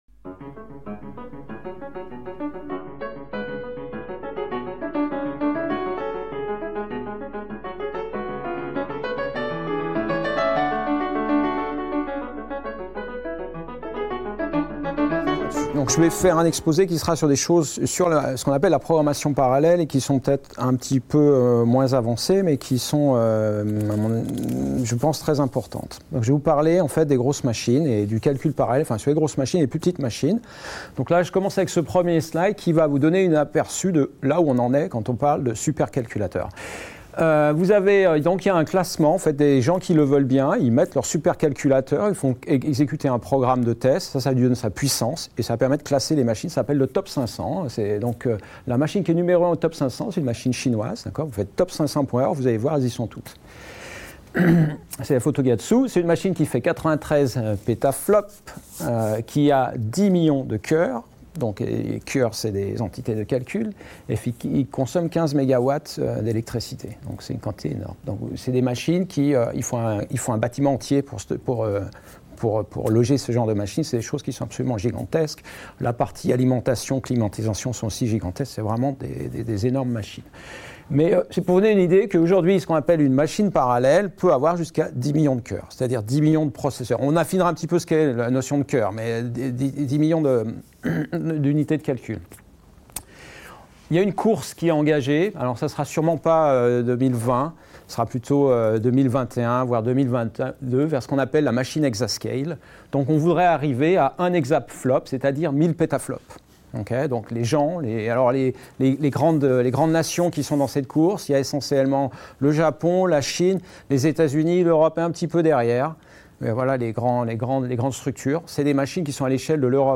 Dans le cadre de cet exposé nous vous ferons découvrir le monde des superclaculateurs actuels et les enjeux de recherche associés.